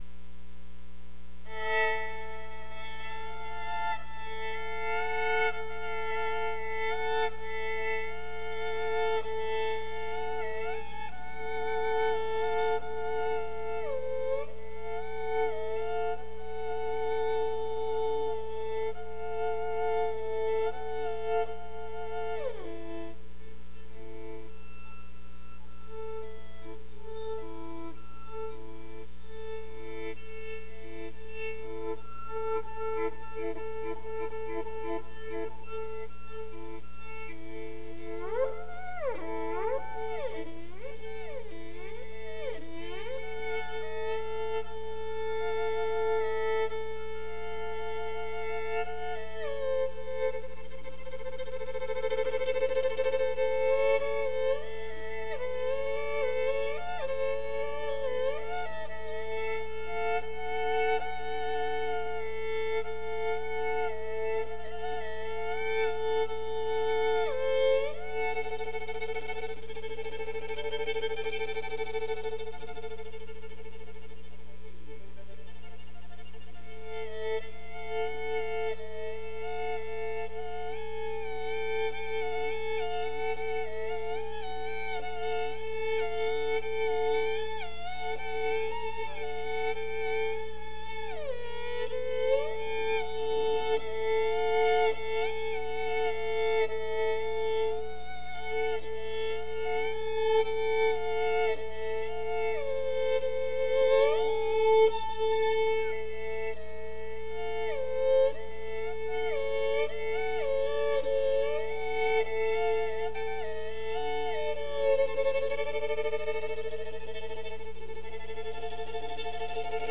Pioneer Works Performance for Amplitude Live.